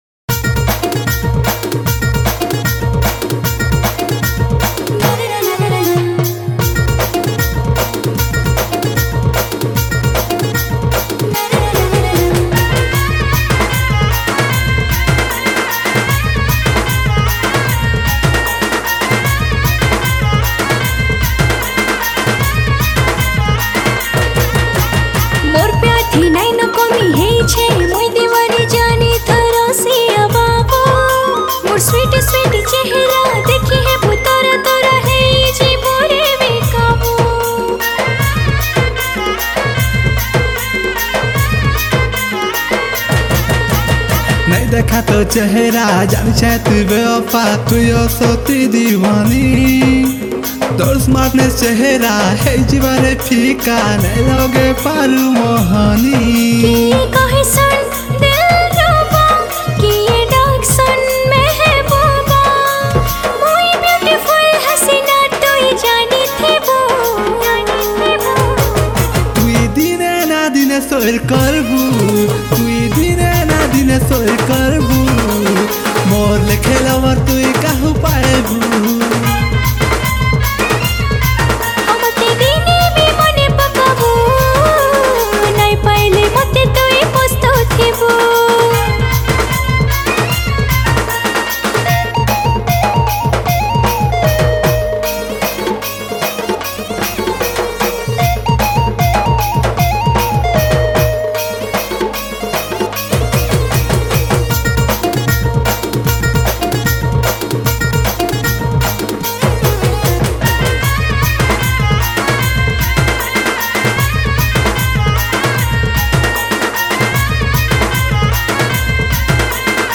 Sambapuri Single Song 2021 Songs Download